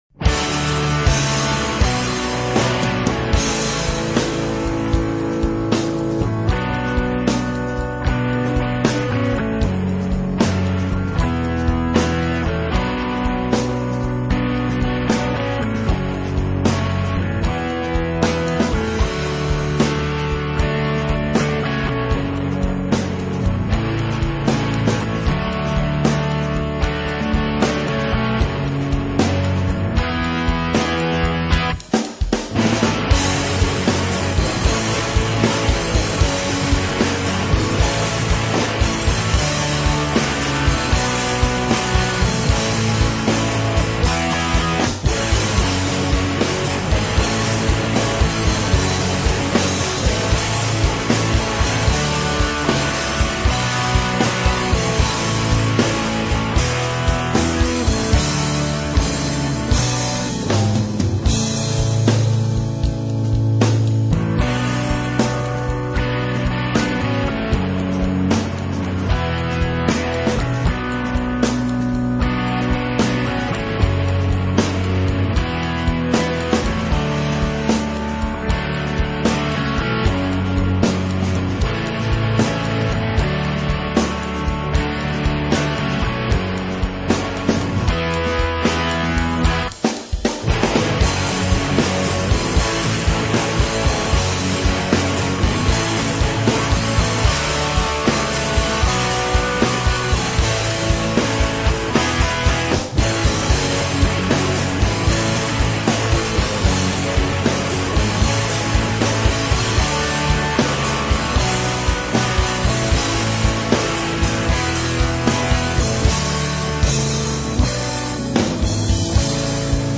rock
hard rock
punk
metal
high energy rock and roll